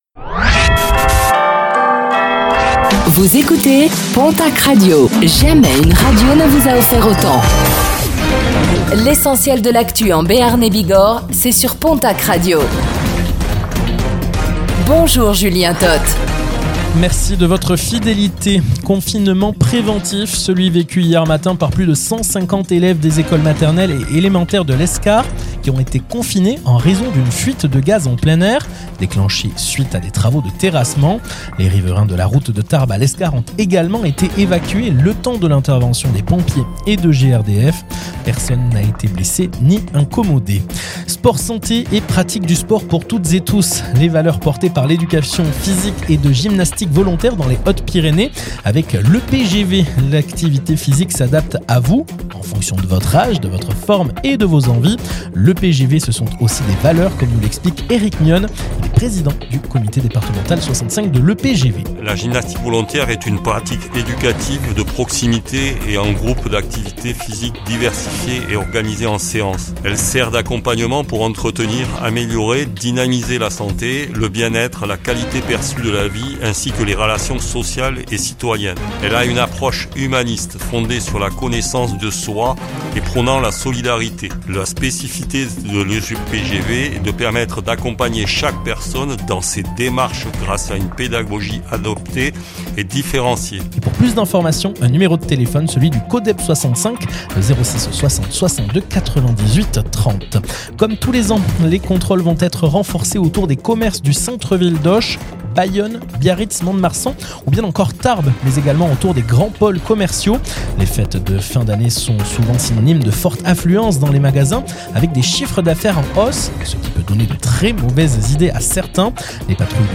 Réécoutez le flash d'information locale de ce mercredi 26 novembre 2025